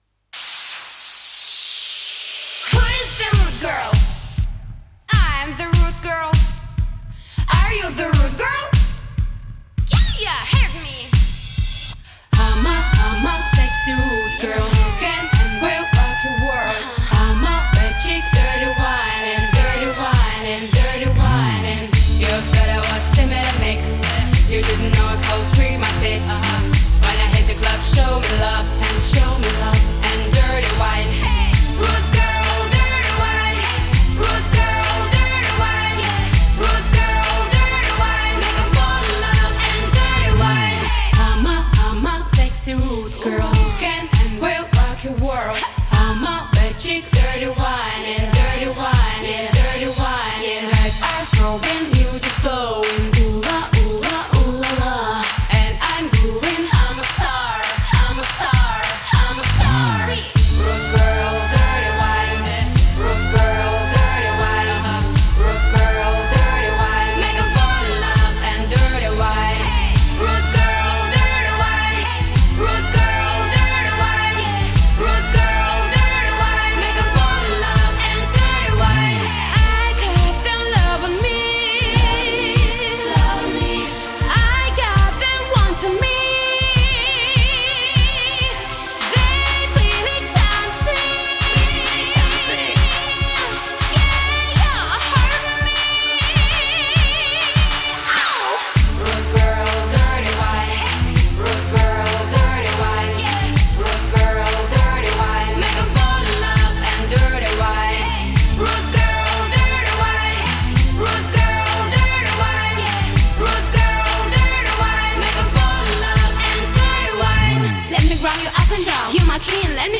энергичная танцевальная композиция в жанре поп и EDM